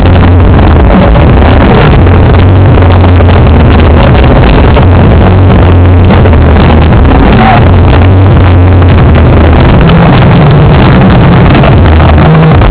Le teknival c’est un grand rassemblement de musique électronique, dégustation de plats épicés, boissons enivrantes et plus si affinités. Cette musique envahi le corps à en faire perdre le rythme cardiaque, une danse individuelle à faire oublier les 40000 personnes autour.